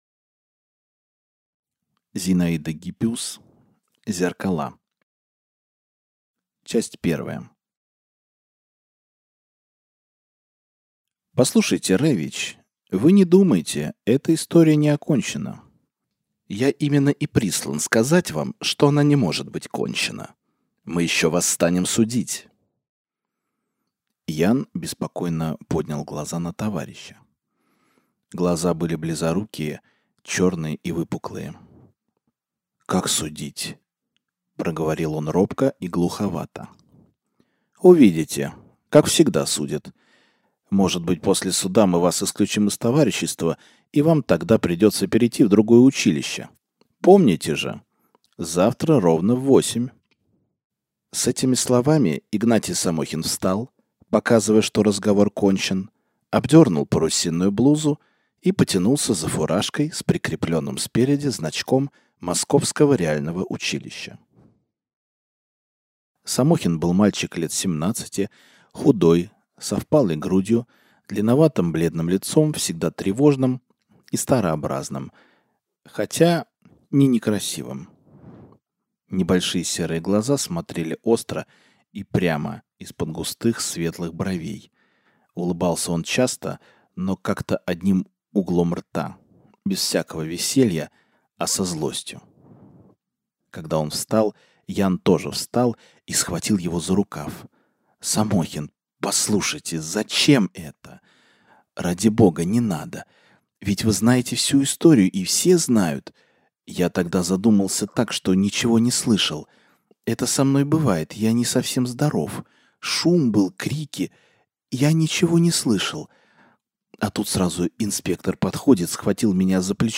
Аудиокнига Зеркала | Библиотека аудиокниг